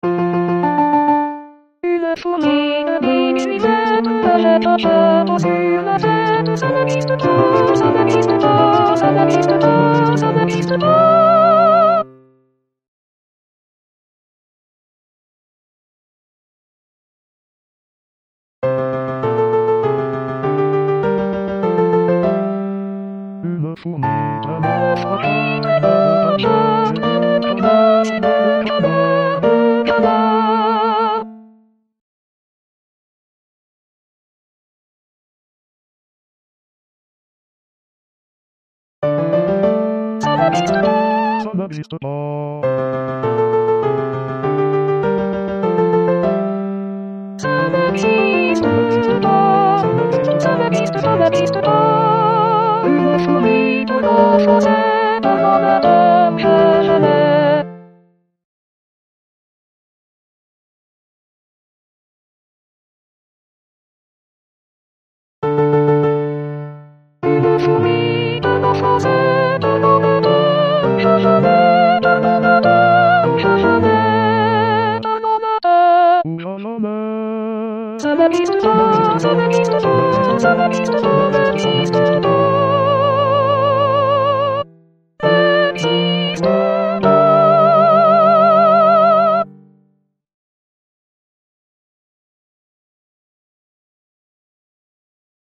La Fourmi (2voix/piano)